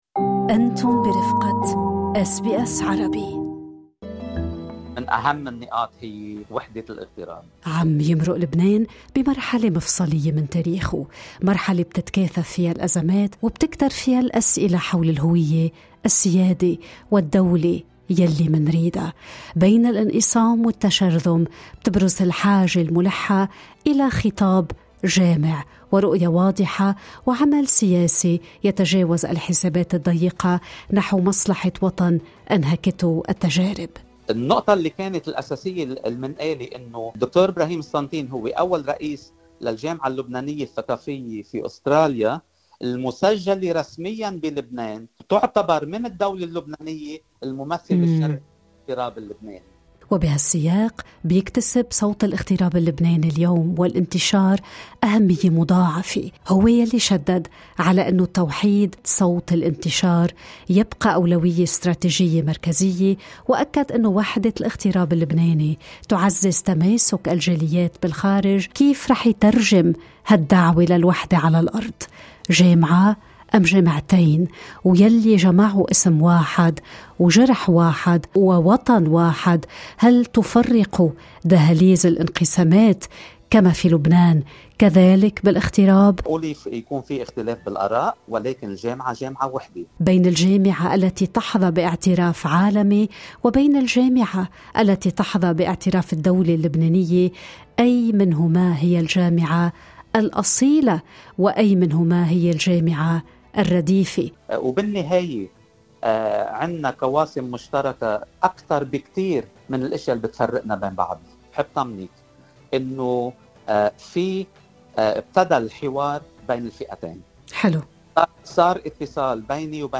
in-depth conversation